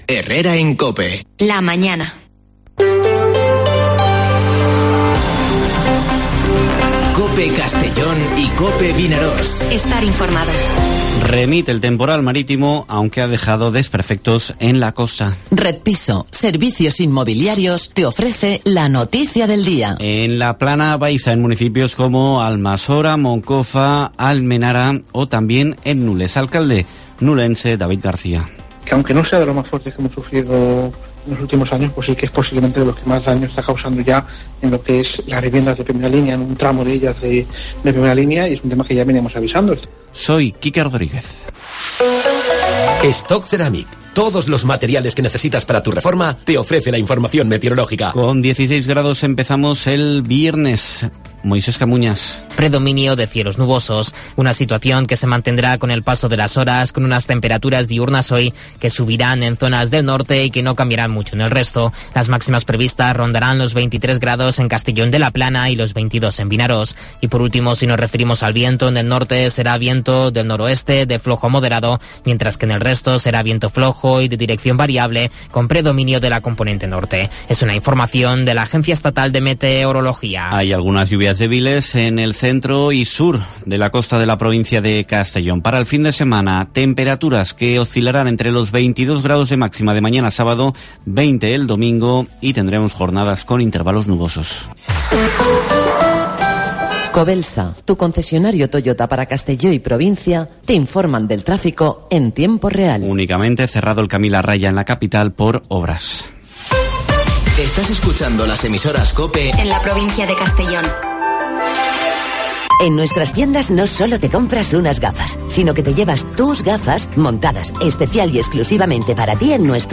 Informativo Herrera en COPE en la provincia de Castellón (12/11/2021)